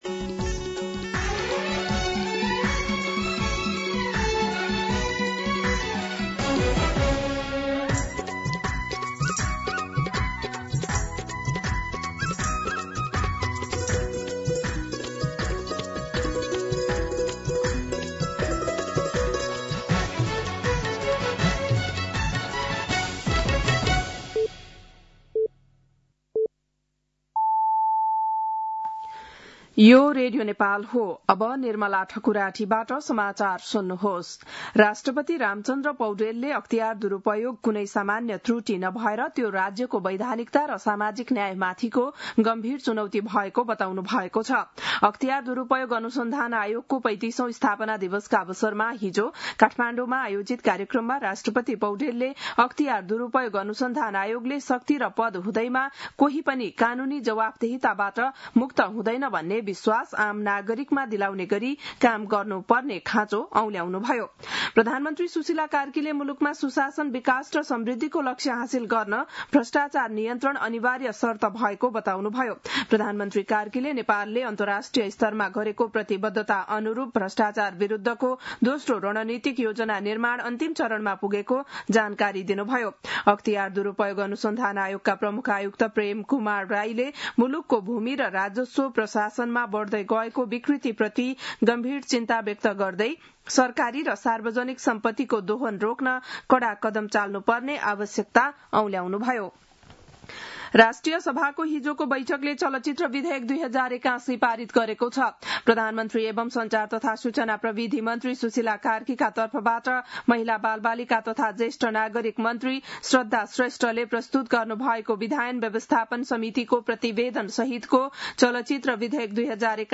बिहान ११ बजेको नेपाली समाचार : २९ माघ , २०८२